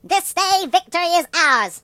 project_files/Data/Sounds/voices/British/Victory.ogg